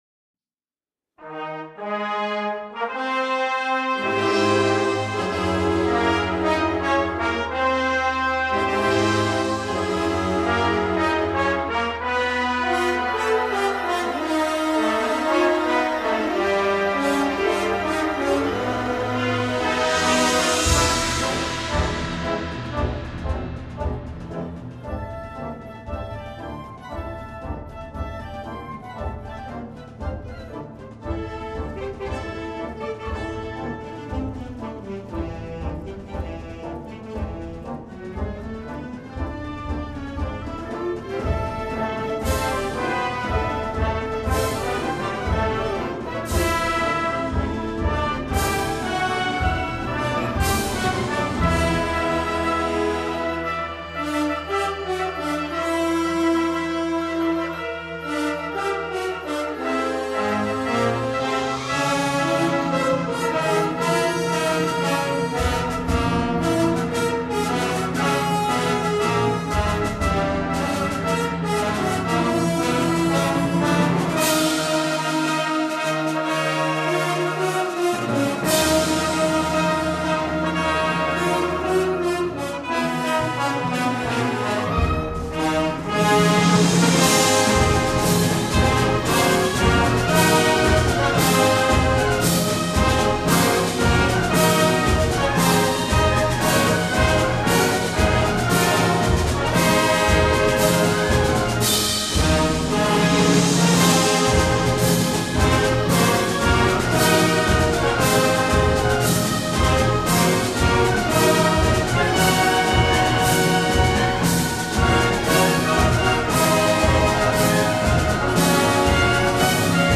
Gender: Christian marches